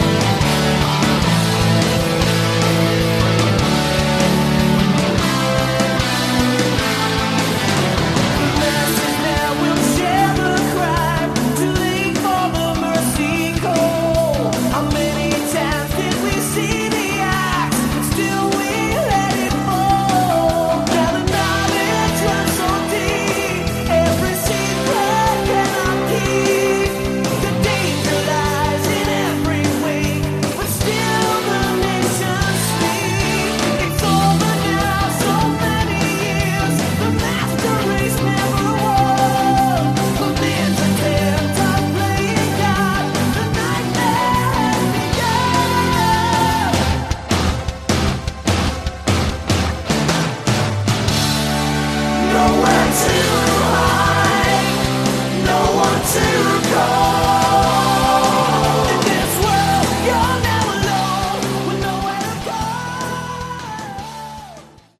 Category: Hard Rock
guitars, lead and backing vocals, keyboards
drums, percussion